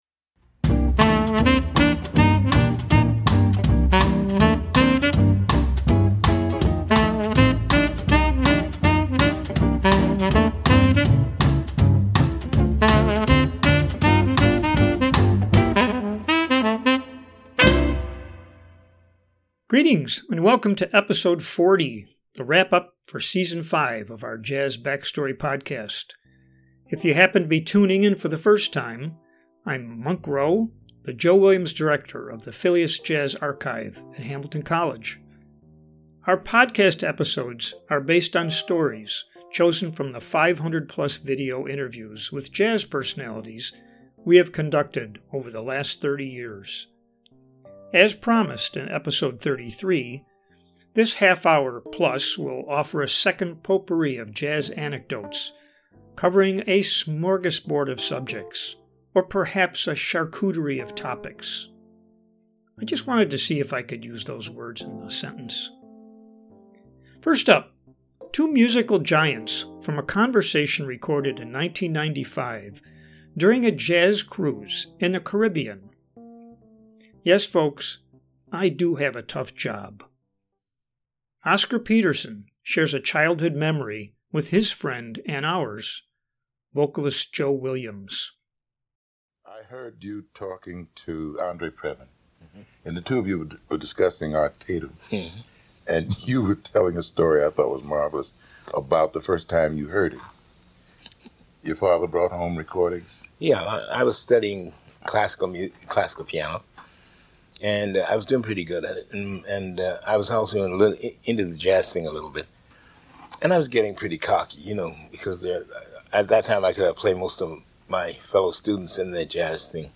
Jazz Backstory podcast episodes will feature interview excerpts focused on topics inherent to the creative life. Artists, both famous and unsung, relate these tales in their own jazz inflected vocabulary. Original music and commentary from the host help set the tone, both educational and swinging.